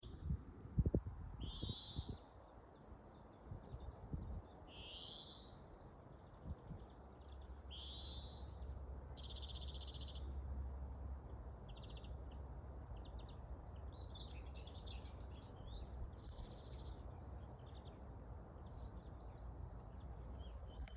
Akmeņčakstīte, Oenanthe oenanthe
StatussDzirdēta balss, saucieni